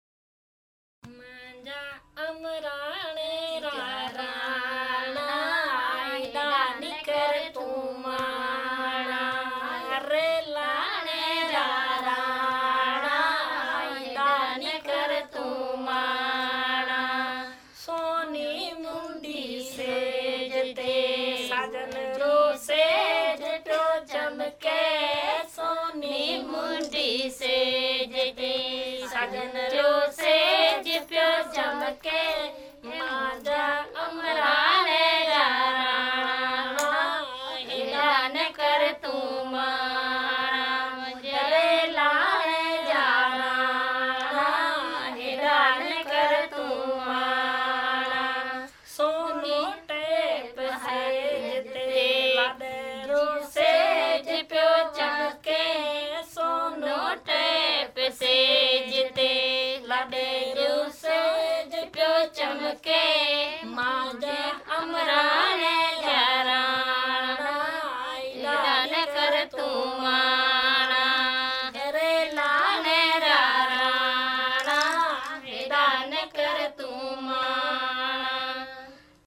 بيئان را گيت | مارواڙي ثقافت
اِيئي رَي ڪري ڪہ ٿي مارواڙي بيئان را گيت ښُڻو ھانَ ياد ڪرو۔